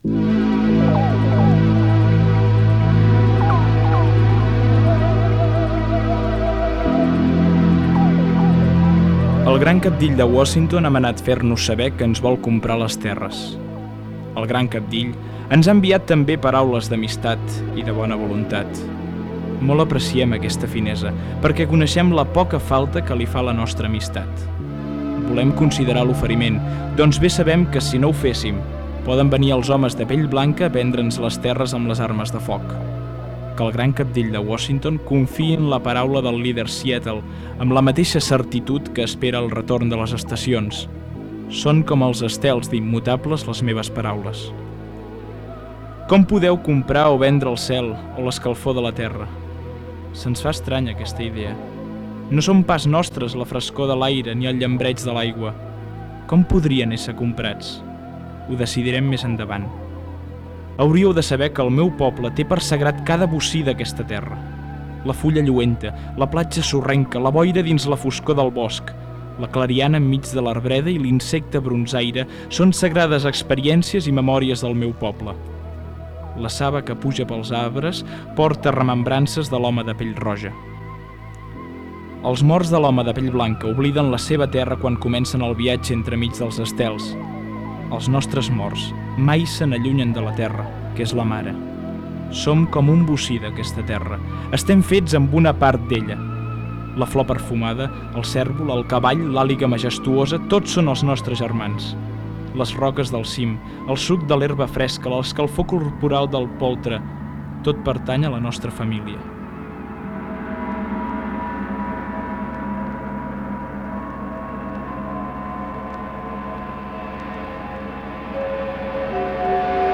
Fragment del primer programa. Lectura del text "Així s'acaba la vida"
Entreteniment